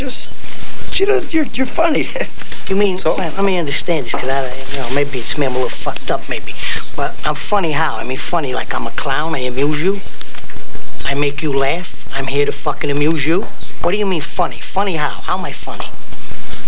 Below, I have included some information, pictures and some of my favorite sound clips from the film.